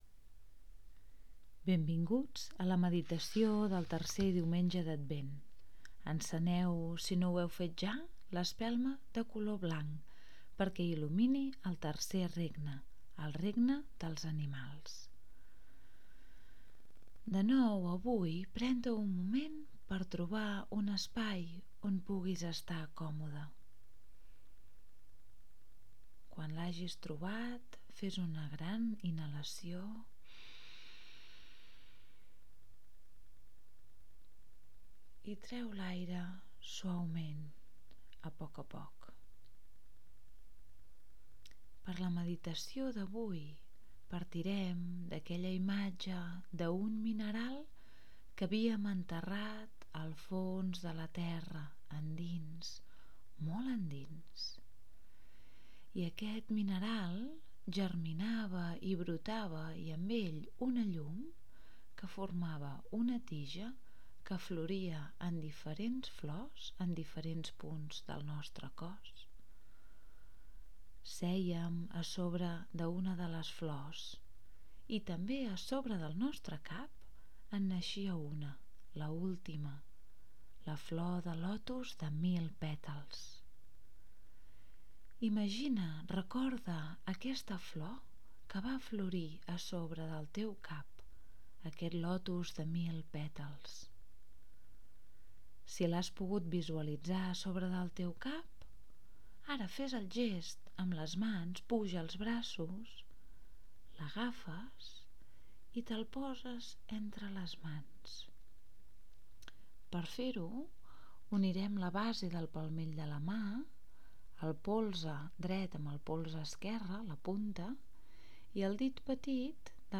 AUDIO de la MEDITACIÓ Regne Animal